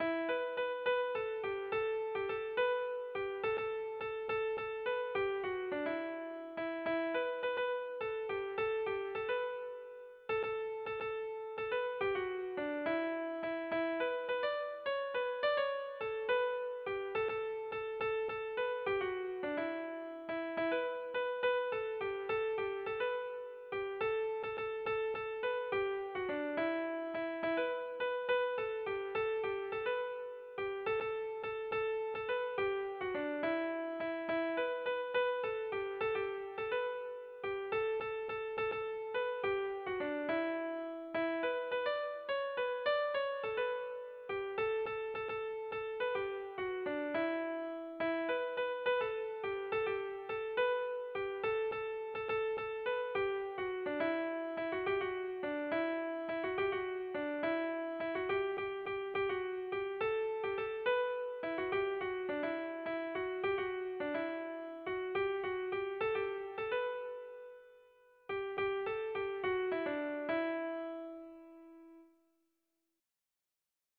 While spoken prayers are more common for this, i'm a musician so I chose to write one song that does the whole thing.